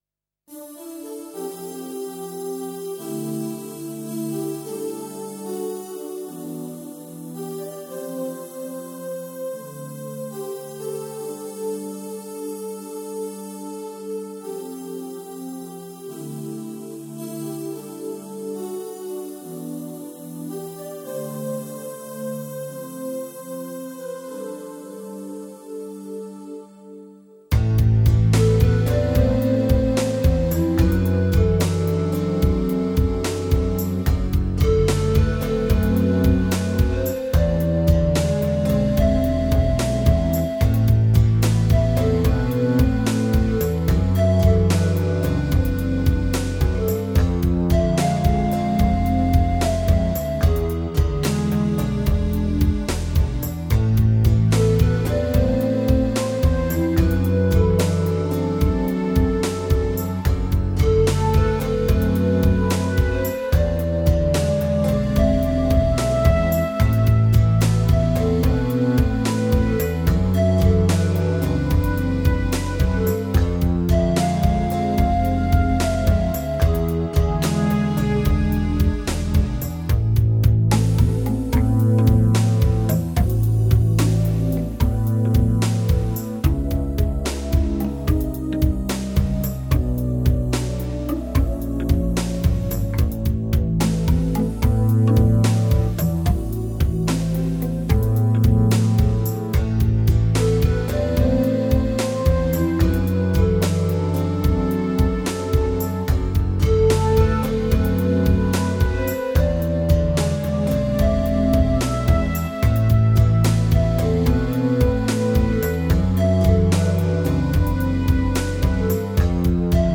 minimalistic lyrical content
sensuous melodies and hypnotic rhythm patterns